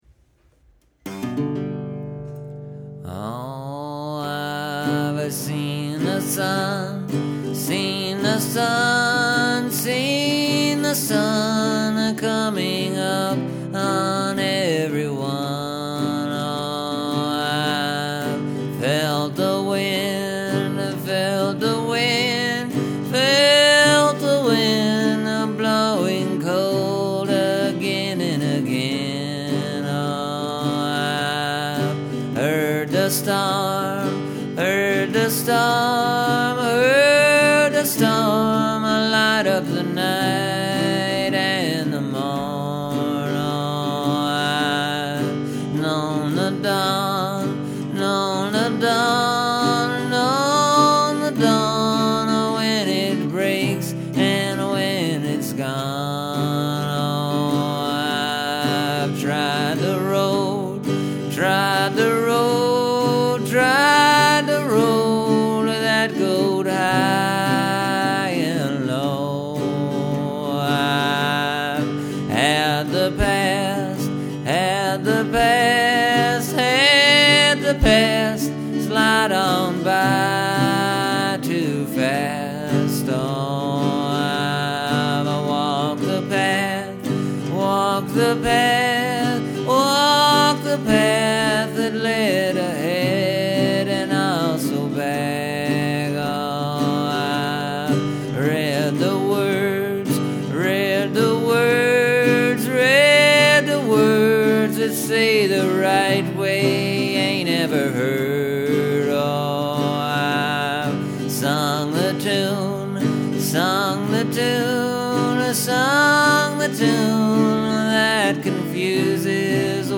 Kind of a more laid back number.